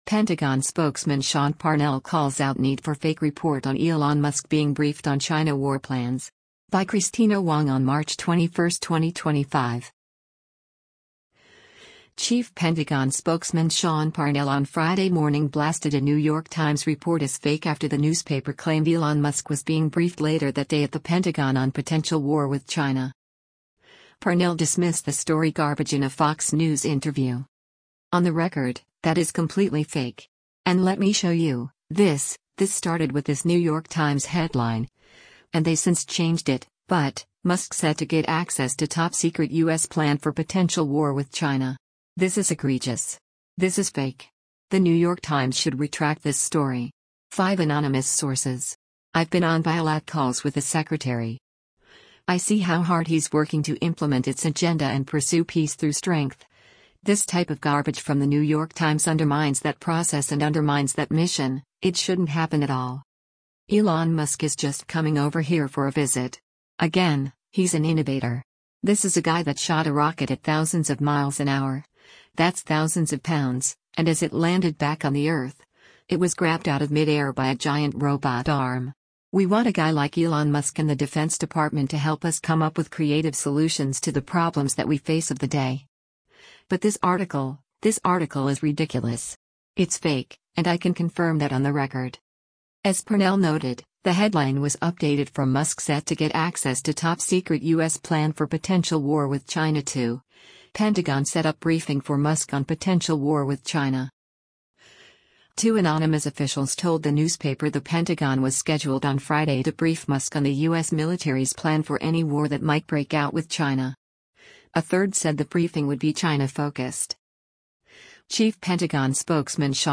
Parnell dismissed the story “garbage” in a Fox News interview.